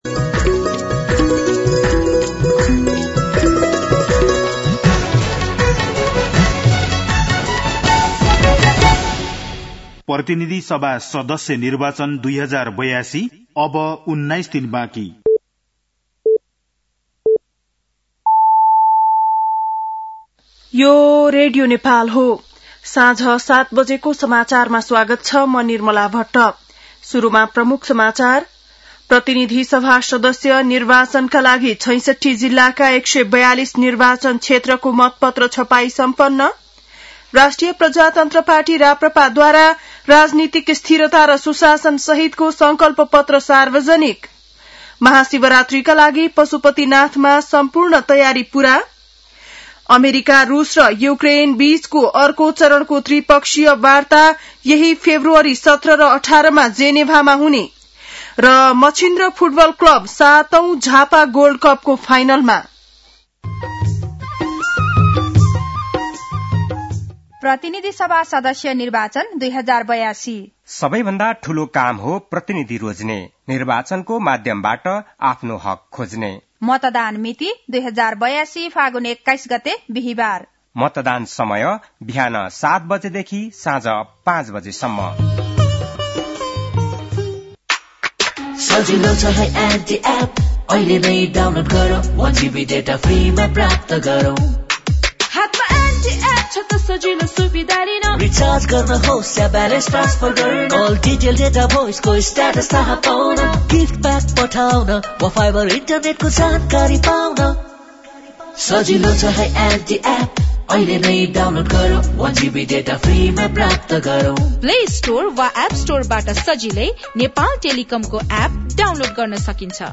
बेलुकी ७ बजेको नेपाली समाचार : २ फागुन , २०८२
7.-pm-nepali-news-1-5.mp3